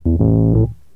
02 Rhodes 12.wav